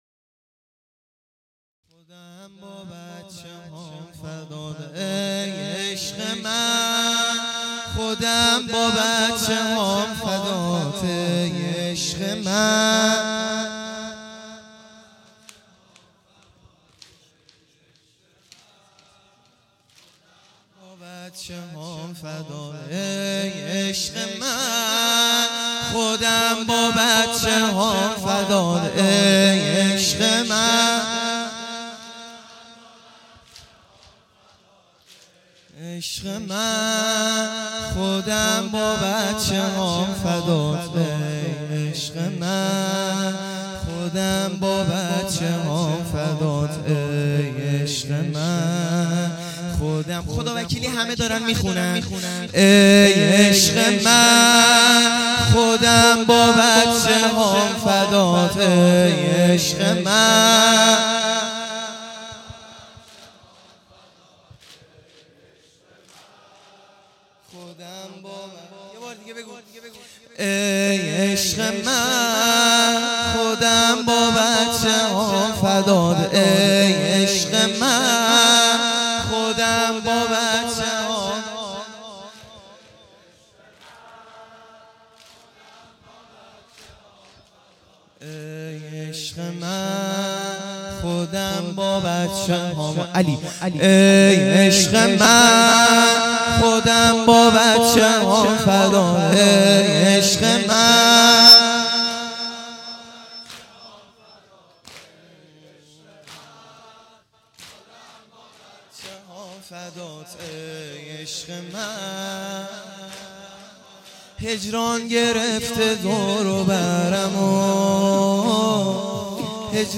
شب چهارم محرم الحرام ۱۳۹۶